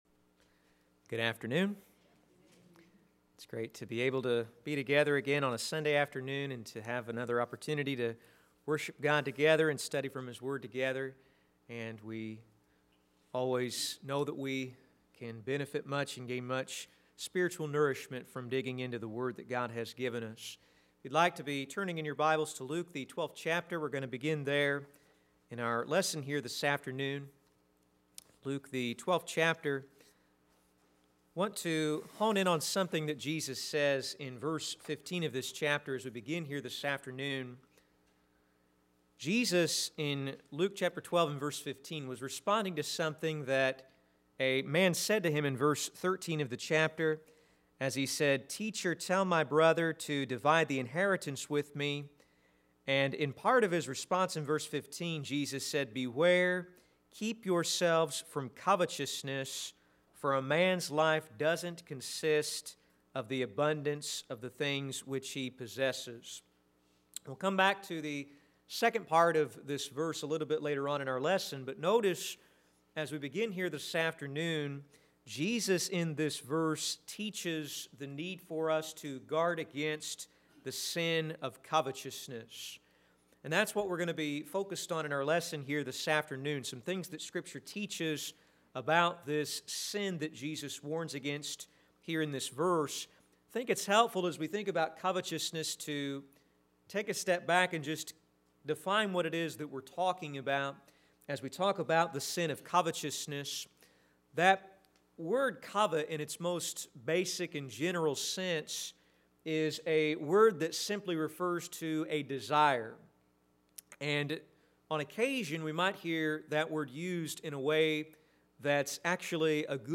Sermons - Olney Church of Christ
Service: Gospel Meeting